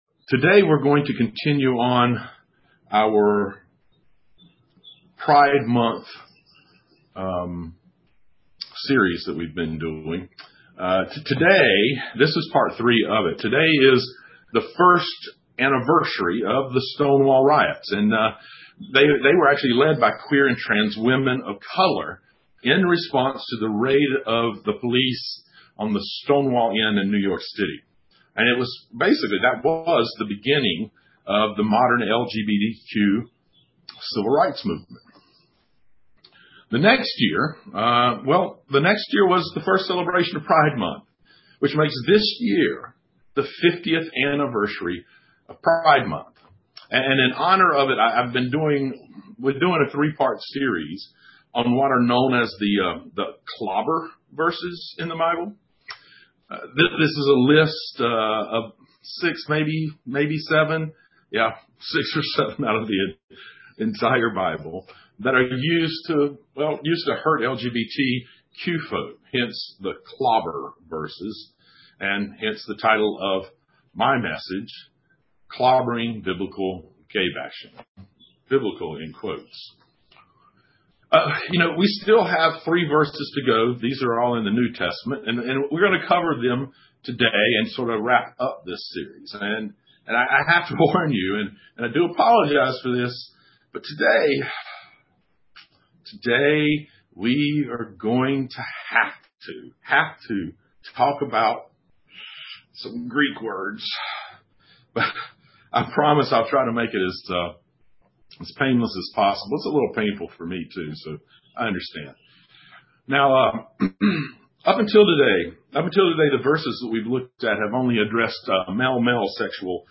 streamed via Facebook and Zoom